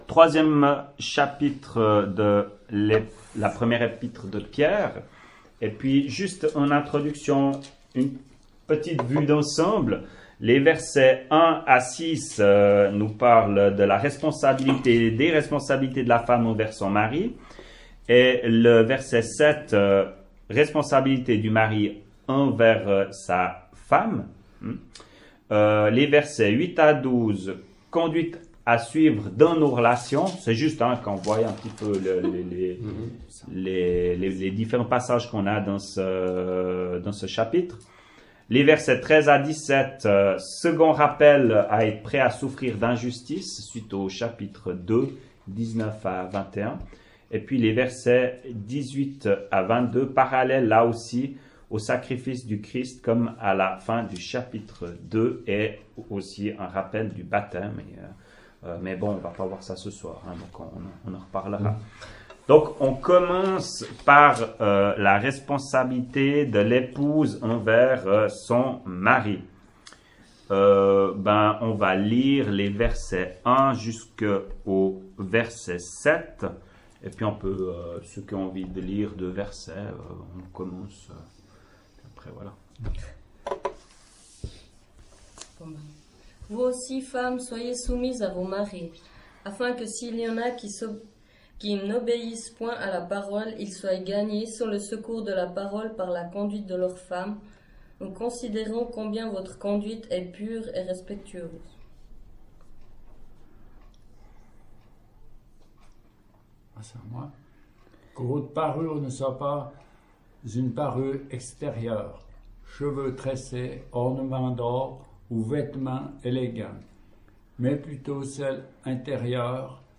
ÉTUDE BIBLIQUE : Evole, le 25.10.2017